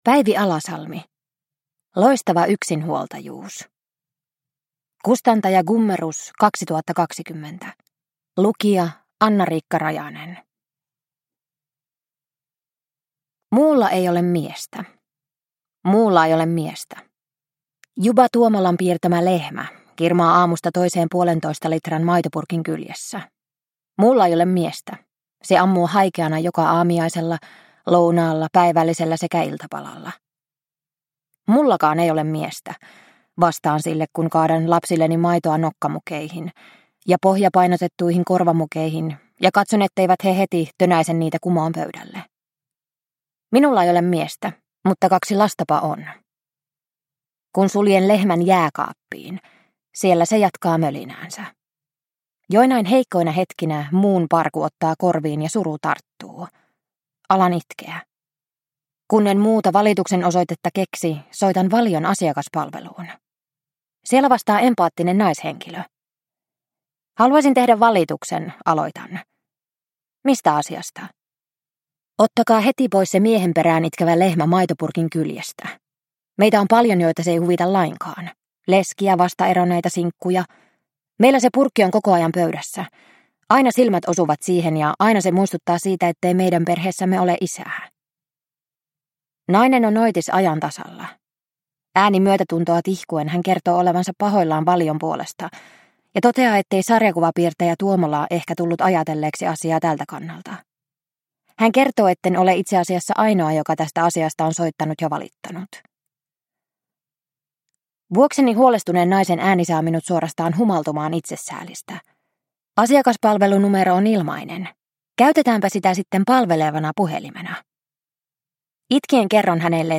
Loistava yksinhuoltajuus – Ljudbok – Laddas ner